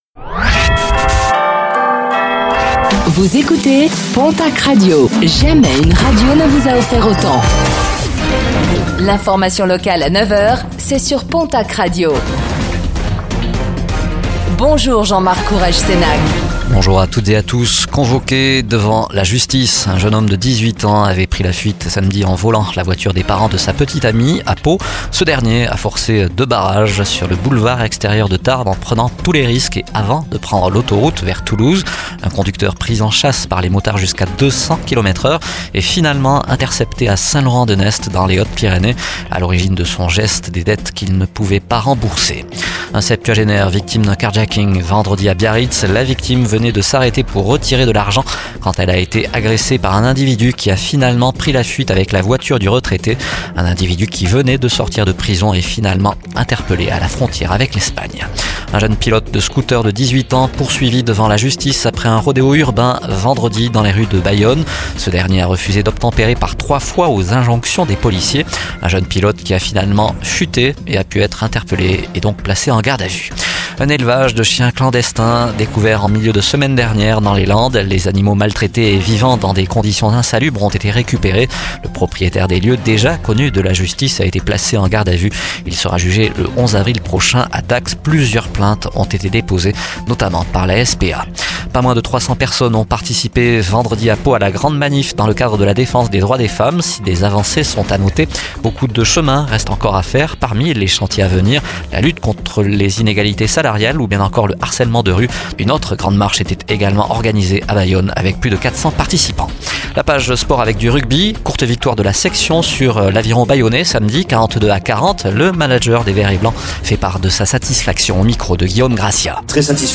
Infos | Lundi 11 mars 2024